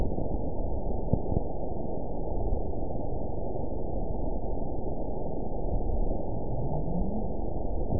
event 920429 date 03/24/24 time 23:44:29 GMT (1 year, 2 months ago) score 9.58 location TSS-AB01 detected by nrw target species NRW annotations +NRW Spectrogram: Frequency (kHz) vs. Time (s) audio not available .wav